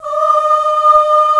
FEM 6 D#4.wav